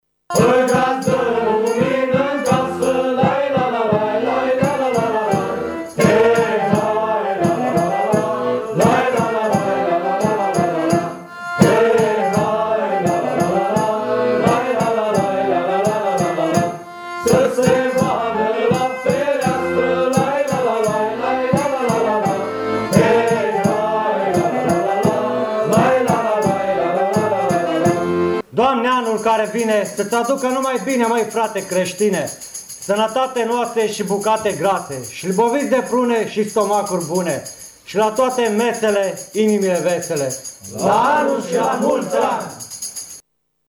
În fiecare an, la ceas de sărbătoare, grupul vocal-instrumental al Inspectoratului pentru Situaţii de Urgenţă „Horea” al judeţului Mureş porneşte la colindat.
Astăzi, pompierii mureșeni au poposit și la Radio Tîrgu-Mureş.
pompierii n-au fost lăsaţi să scape cu una cu două aşa că au ajuns în direct la „Pulsul Zilei”
unde au colindat pentru toţi ascultătorii postului nostru